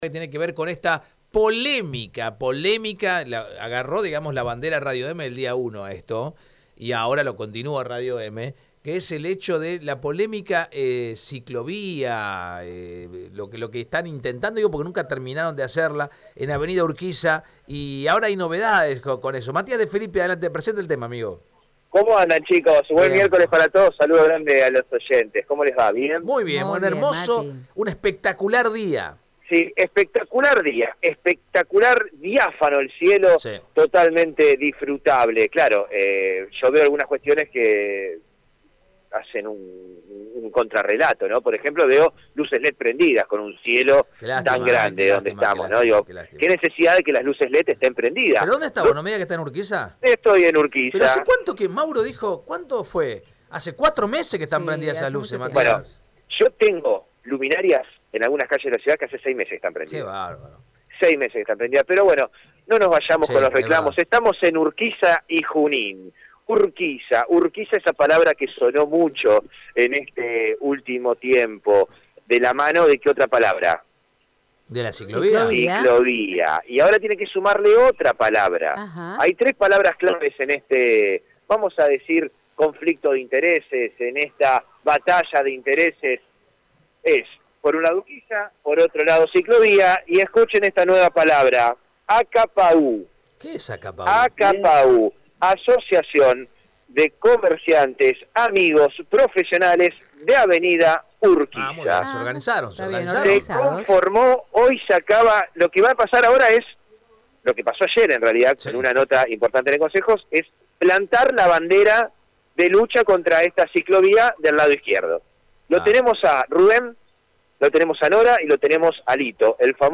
Escuchá la nota completa con los representates de la Asociación